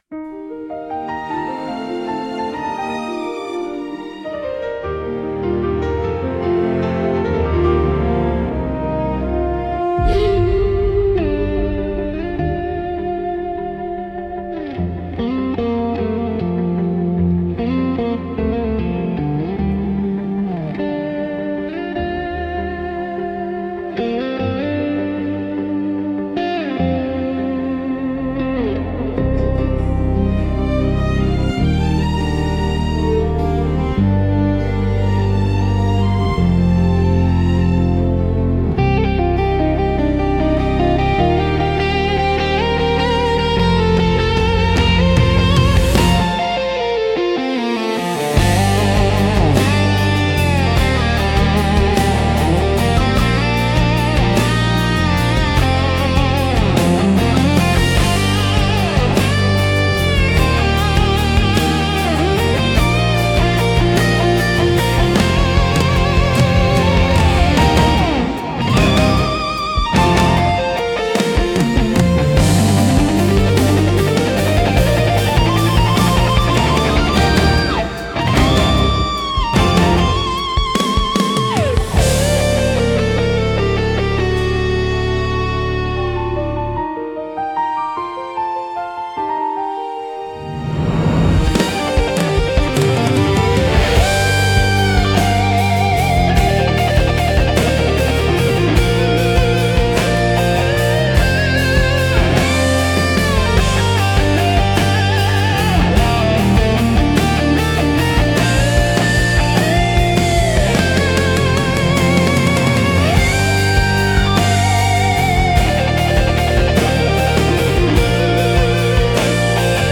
聴く人に新鮮な発見と共感をもたらし、広がりのある壮大な空間を演出するジャンルです。